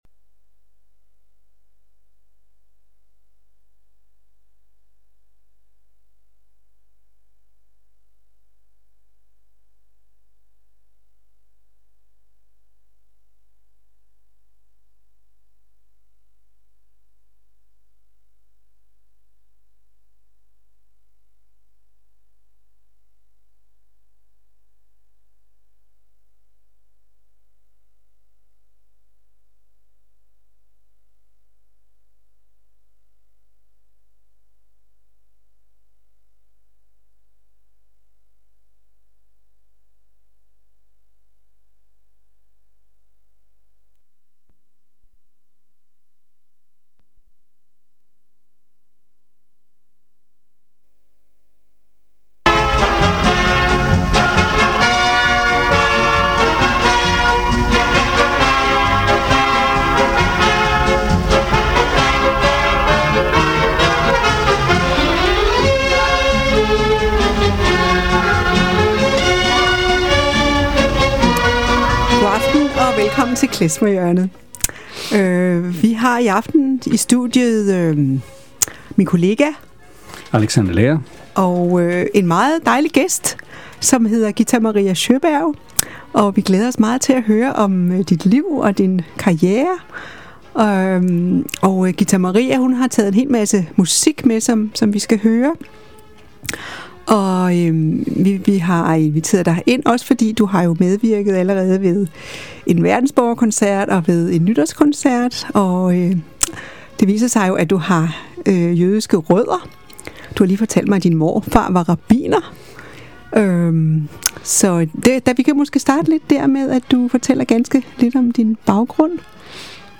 Beskrivelse:Interview